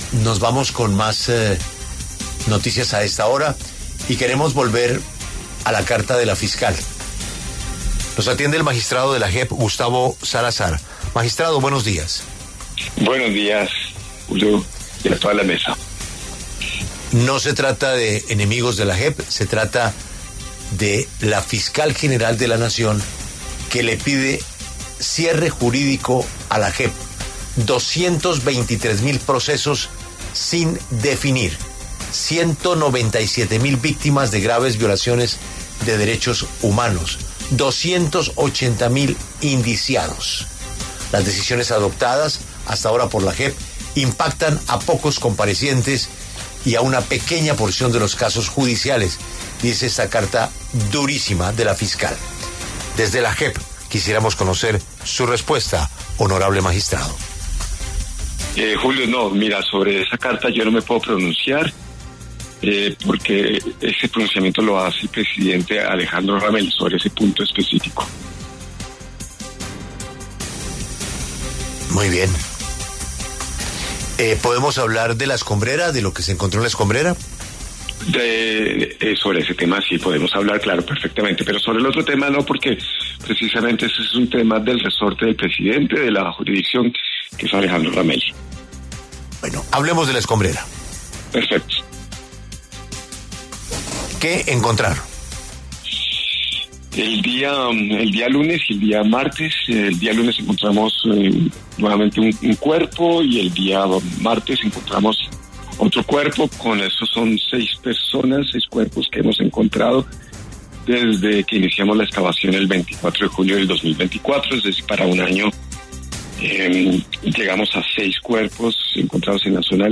Gustavo Salazar, magistrado de la JEP, pasó por los micrófonos de La W para hablar sobre el hallazgo de dos cuerpos más en las labores de búsqueda en La Escombrera.